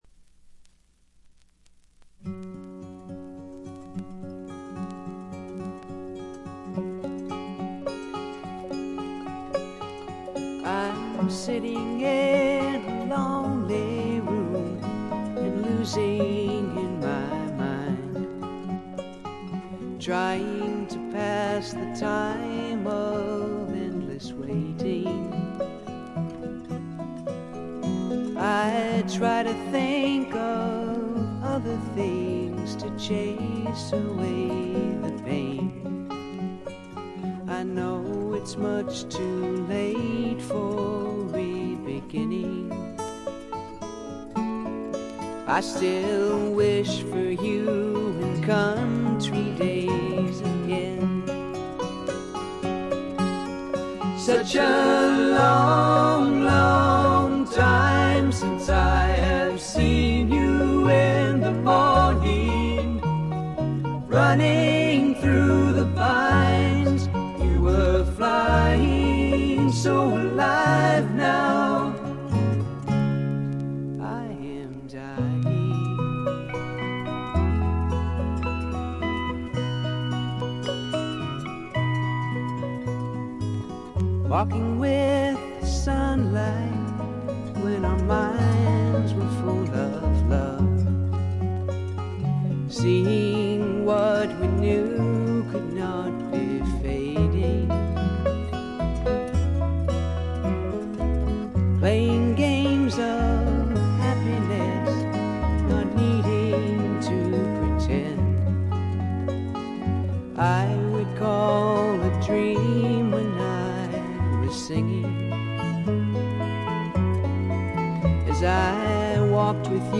部分試聴ですがわずかなチリプチ程度。
試聴曲は現品からの取り込み音源です。